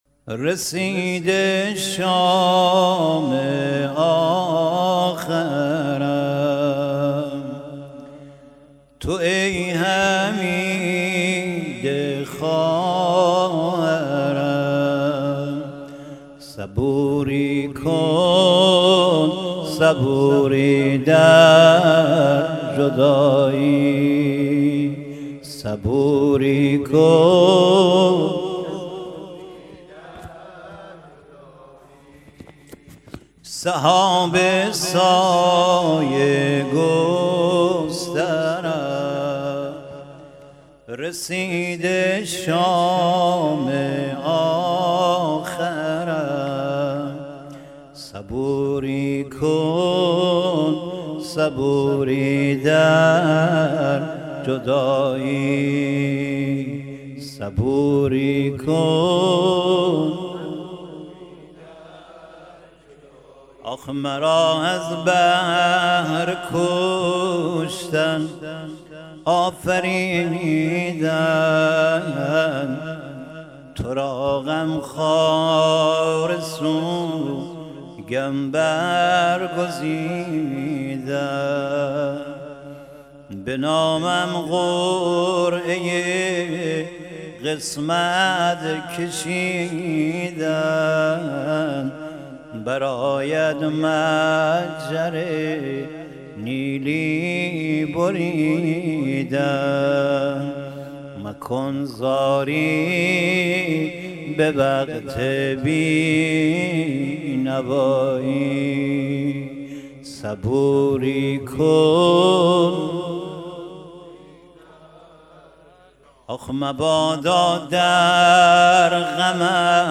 زمزمه صبوری کن صبوری در جدایی
محرم و صفر 1400 و 1401 شمسی